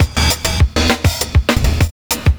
100CYMB01.wav